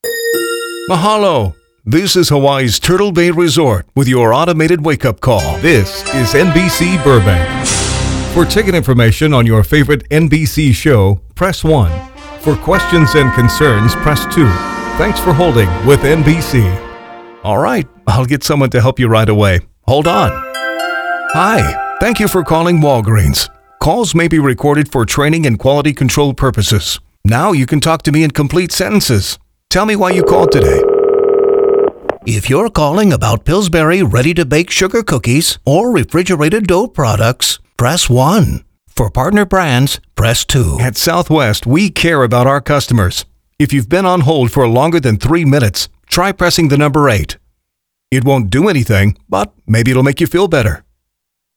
Male
Trans-Atlantic
I have a dynamic voice range, from conversational and relatable, to energetic and powerful announcer.
Microphone: Neumann U87, Sennheiser MKH416